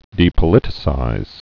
(dēpə-lĭtĭ-sīz)